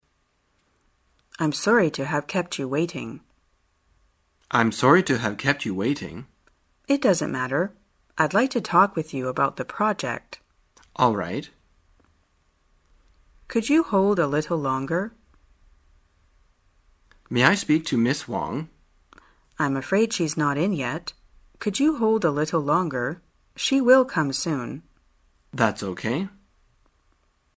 在线英语听力室生活口语天天说 第77期:怎样表示歉意的听力文件下载,《生活口语天天说》栏目将日常生活中最常用到的口语句型进行收集和重点讲解。真人发音配字幕帮助英语爱好者们练习听力并进行口语跟读。